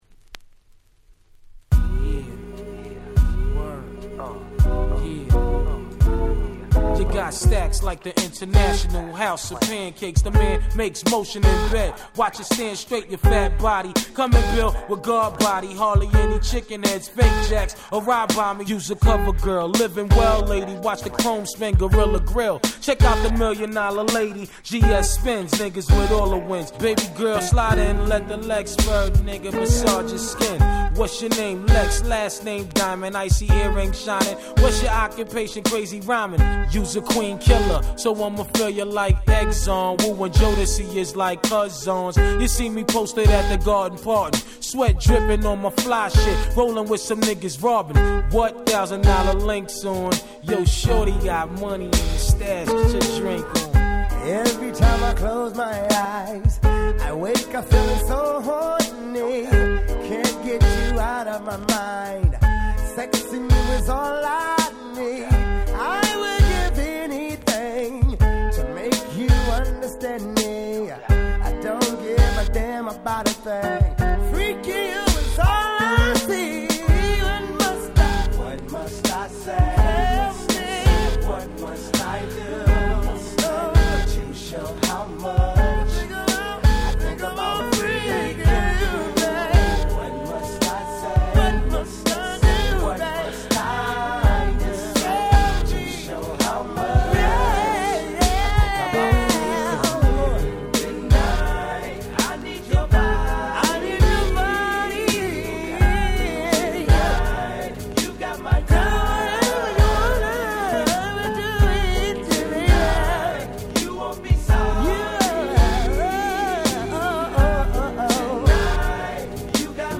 US Promo Only Remix !!
Rare 90's R&B !!
96' Nice Hip Hop Soul !!
バラードだった原曲とは一線を画す、まじで格好良いRemixです！